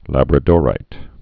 (lăbrə-dôrīt, -dô-rīt)